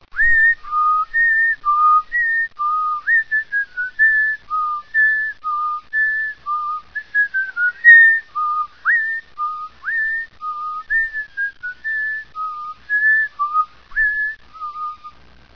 whistle_loop.ogg